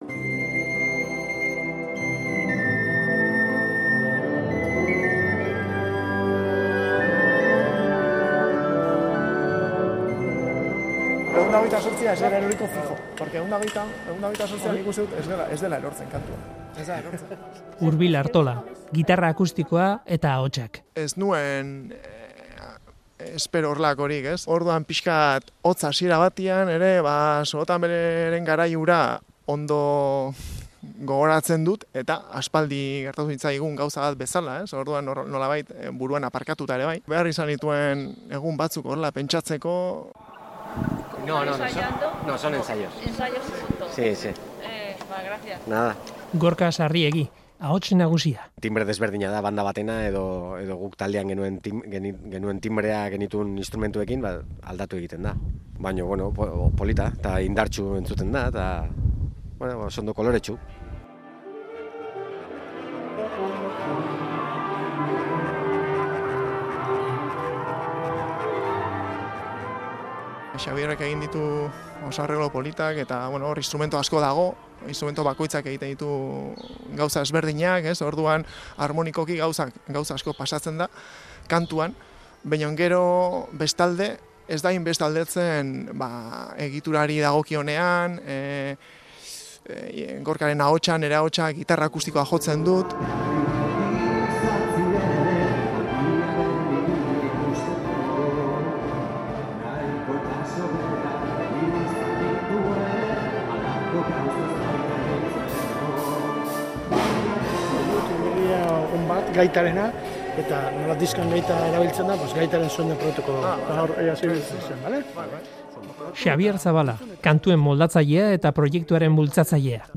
Hondarribian izango dira gaur musika taldeko kideak “Sorotan Bele Gogoan” proiektuak antolatutako kontzertuan. 25 urte atzera eginda gogoratu dituzte garaiko kontuak gaur iluntzean. Hondarribian izan da Euskadi Irratia.